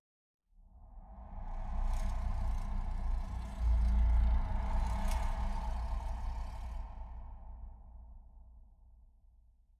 Minecraft Version Minecraft Version snapshot Latest Release | Latest Snapshot snapshot / assets / minecraft / sounds / ambient / nether / basalt_deltas / twist1.ogg Compare With Compare With Latest Release | Latest Snapshot